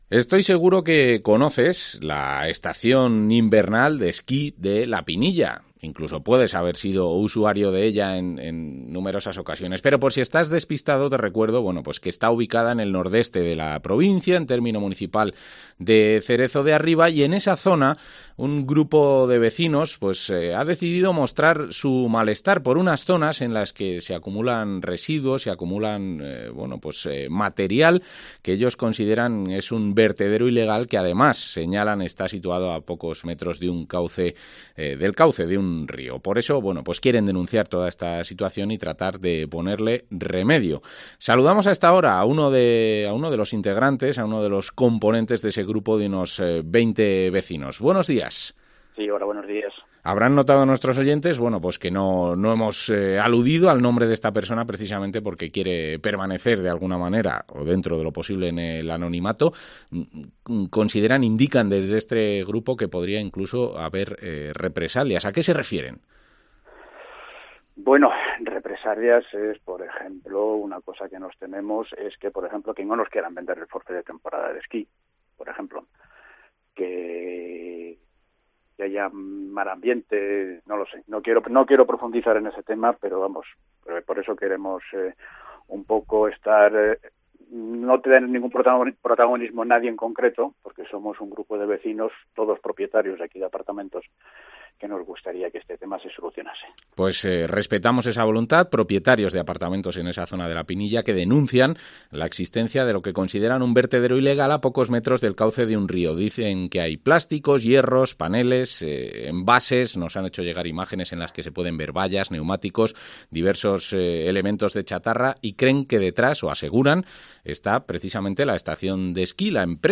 Entrevista vecino La Pinilla